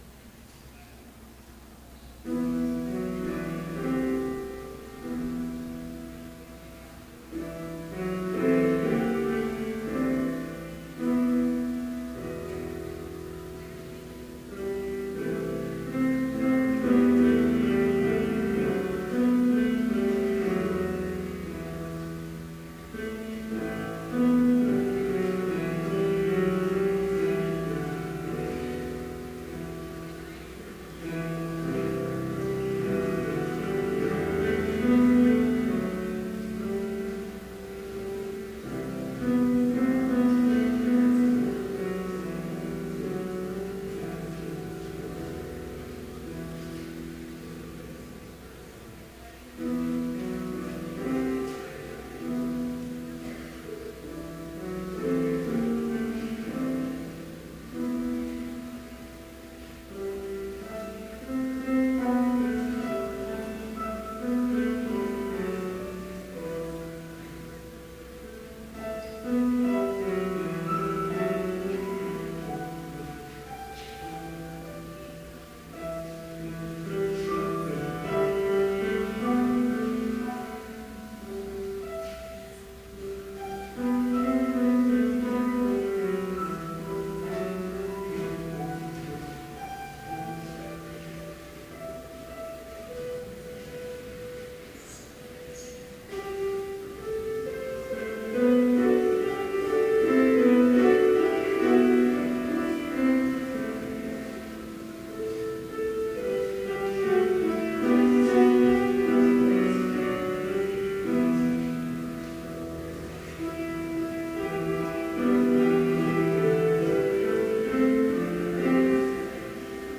Complete service audio for Chapel - February 20, 2015